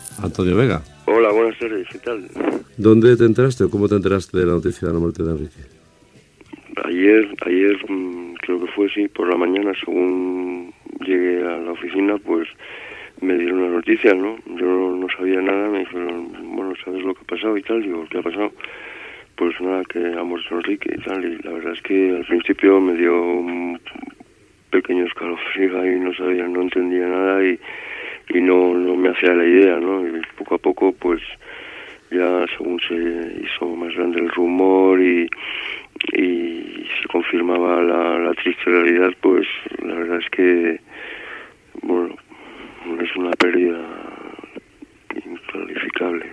Fragment d'una entrevista a Antonio Vega, sobre la mort del cantant Enrique Urquijo.
FM